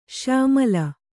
♪ śyāmala